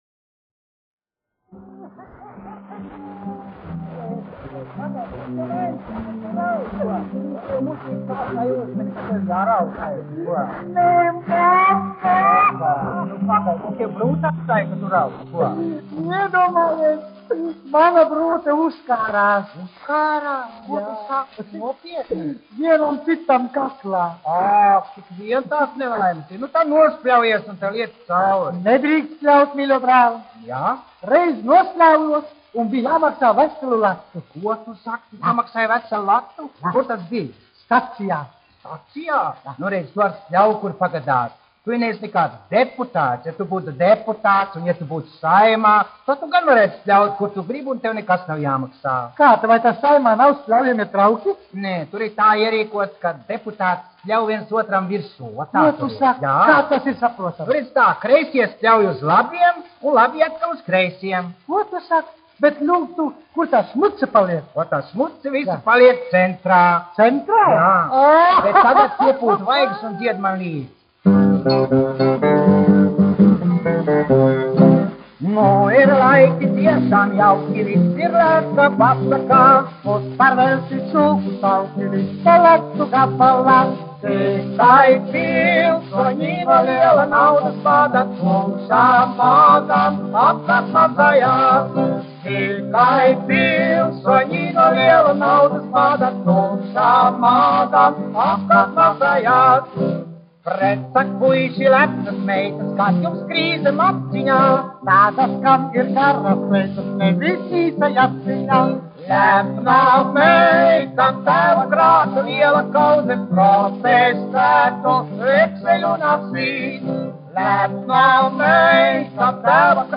1 skpl. : analogs, 78 apgr/min, mono ; 25 cm
Humoristiskās dziesmas
Skaņuplate